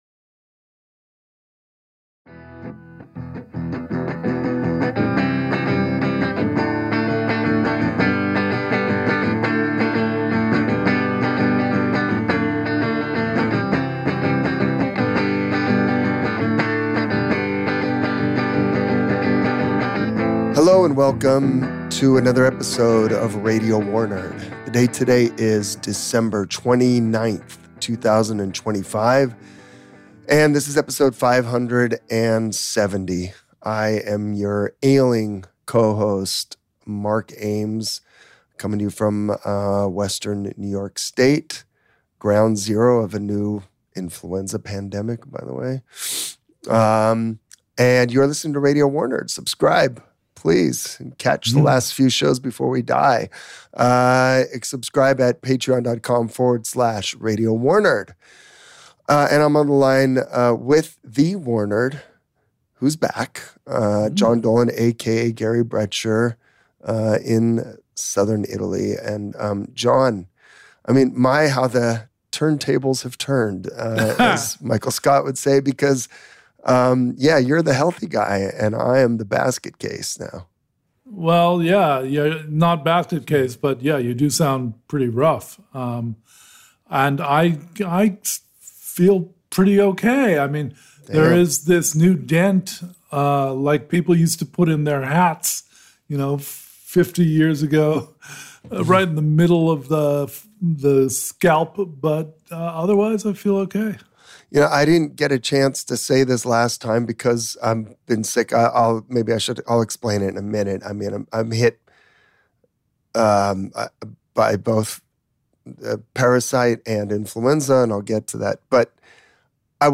Recorded: December 29, 2025 Your WIA co-hosts look back on the worst year yet since the pod launched...